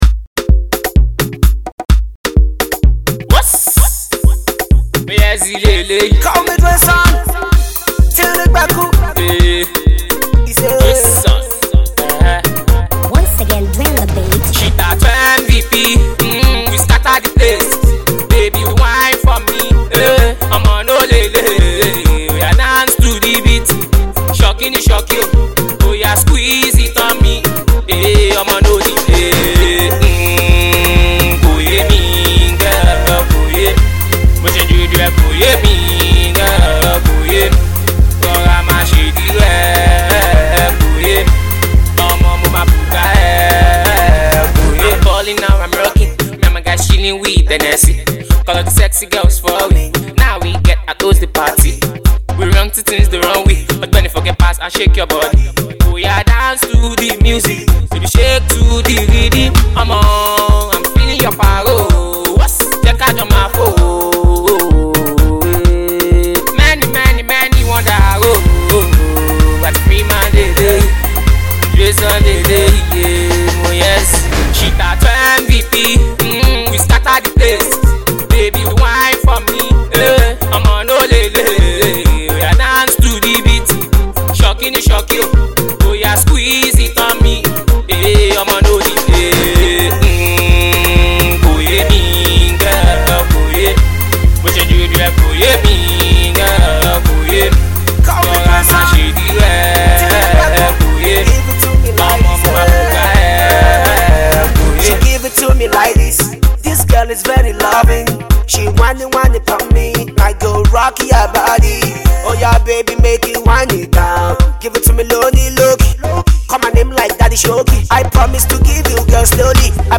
another dope and melodious song